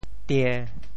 How to say the words 张 in Teochew？
tie~1.mp3